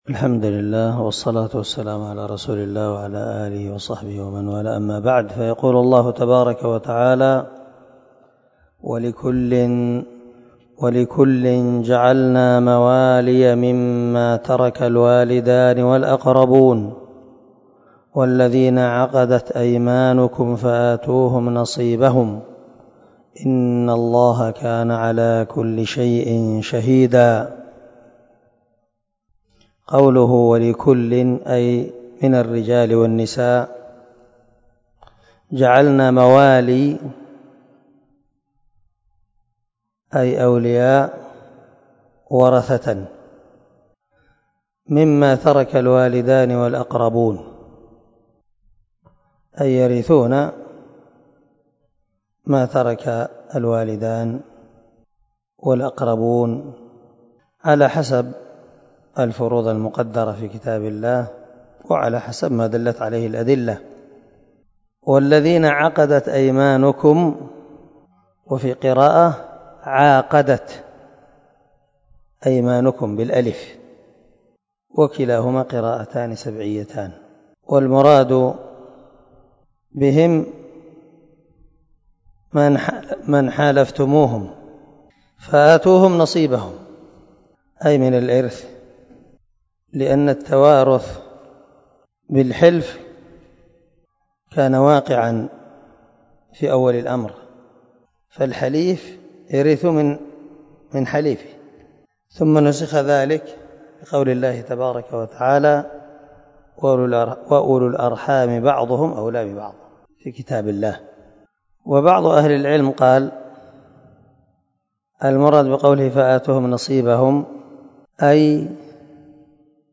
258الدرس 26 تفسير آية ( 33 – 34 ) من سورة النساء من تفسير القران الكريم مع قراءة لتفسير السعدي